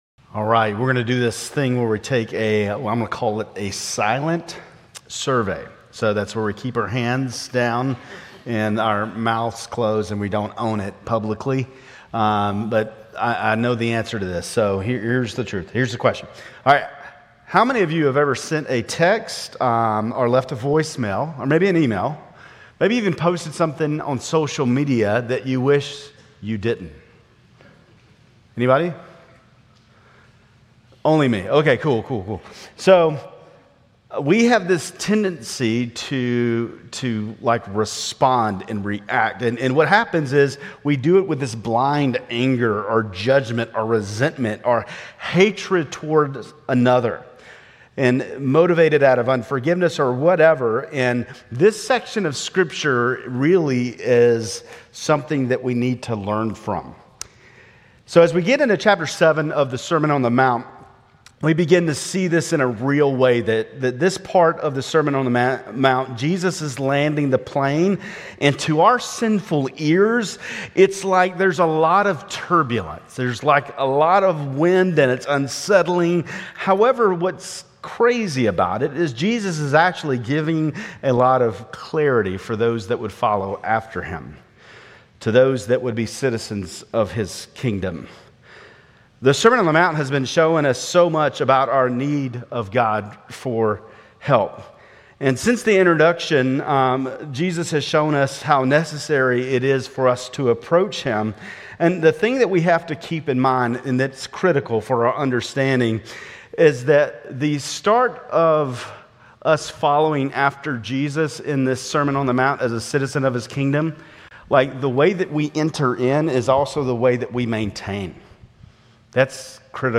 Grace Community Church Lindale Campus Sermons 3_23 Lindale Campus Mar 24 2025 | 00:26:25 1x 00:00 / 00:26:25 Subscribe Share RSS Feed Share Link Embed